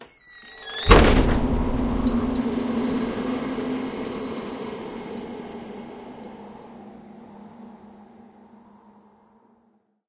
WoodenDoorClose.ogg